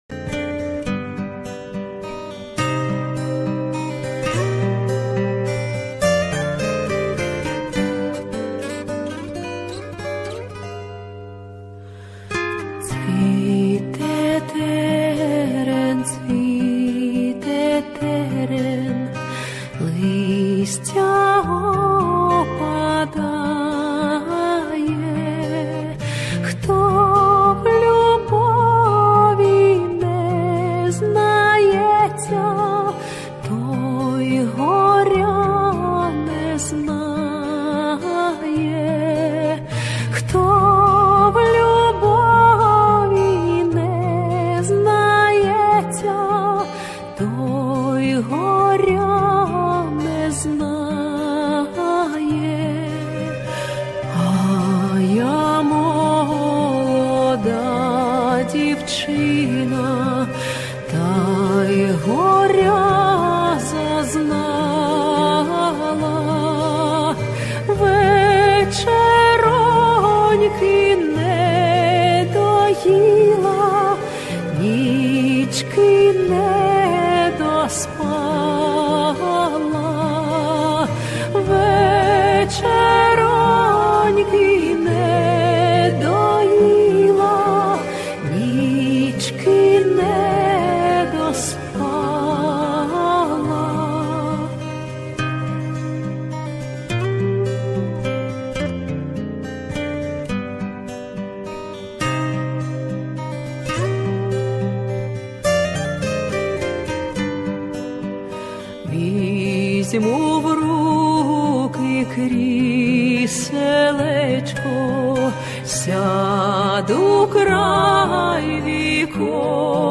les epines fleurissent (chant populaire d Ukraine
ANTONOVA (Elisabeta), les epines fleurissent (chant populaire d Ukraine).mp3